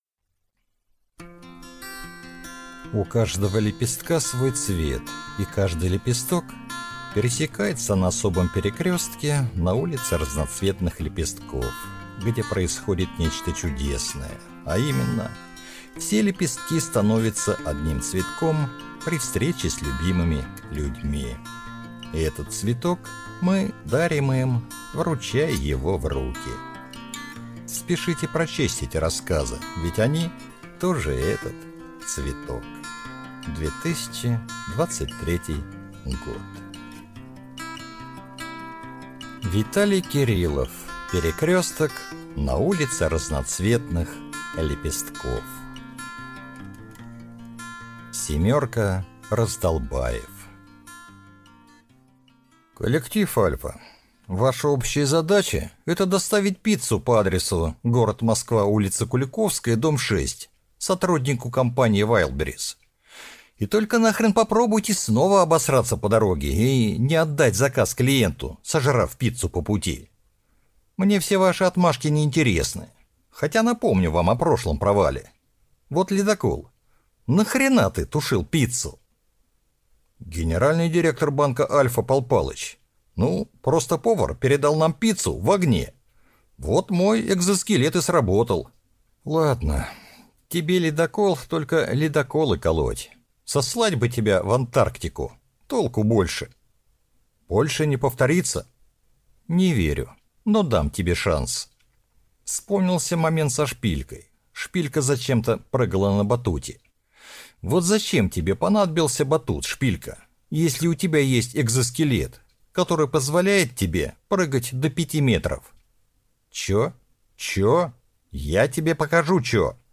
Аудиокнига Перекрёсток на улице Разноцветных лепестков | Библиотека аудиокниг